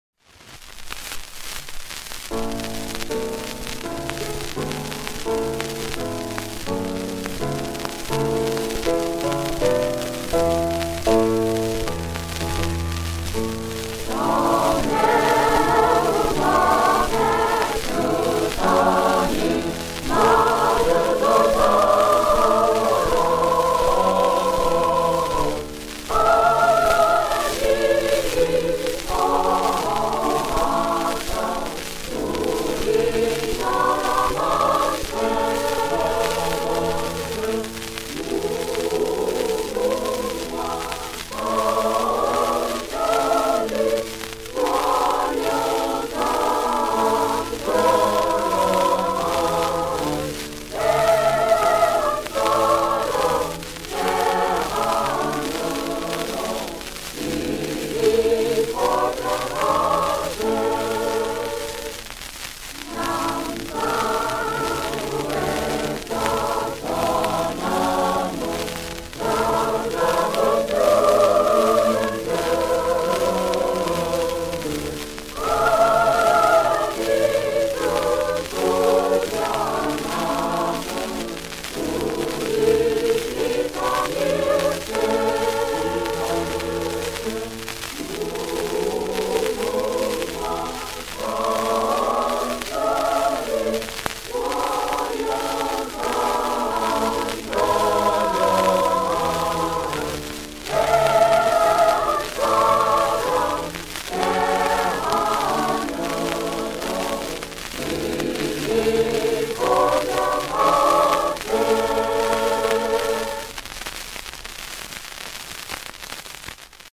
1942년에 불린 애국가 녹음